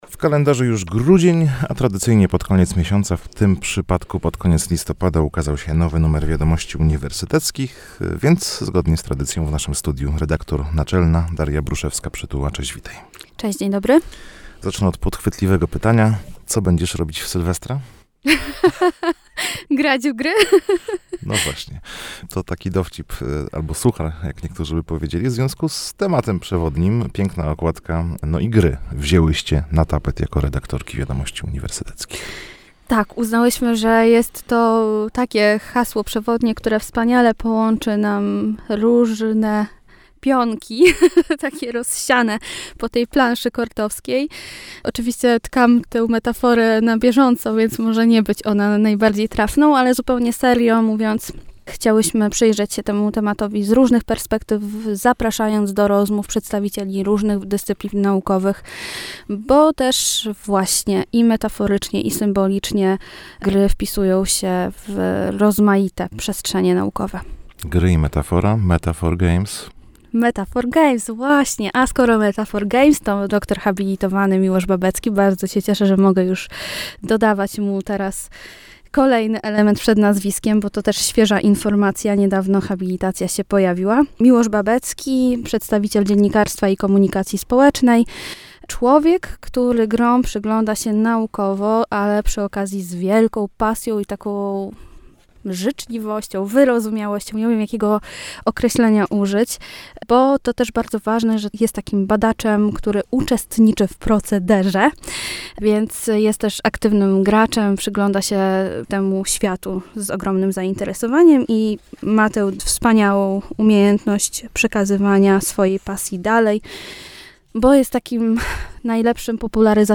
Zapraszamy do wysłuchania rozmowy i... lektury!